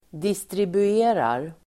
Uttal: [distribu'e:rar]